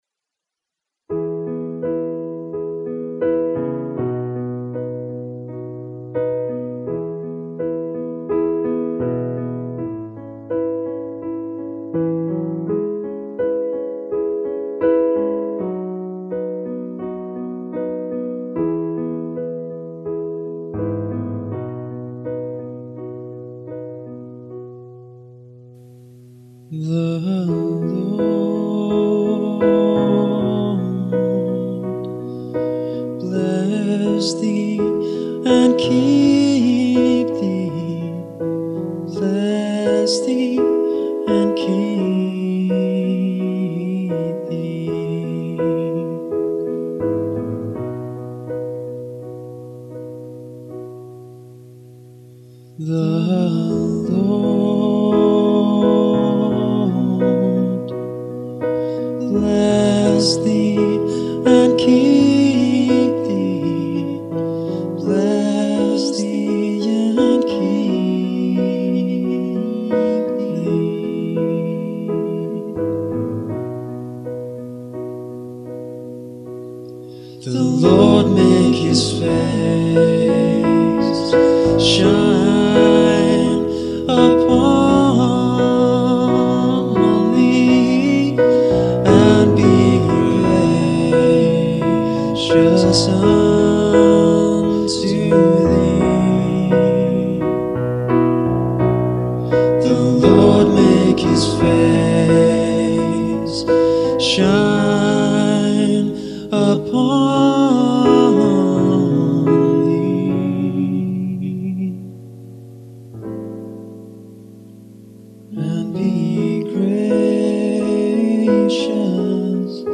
I was driving home and had the piano intro in my head.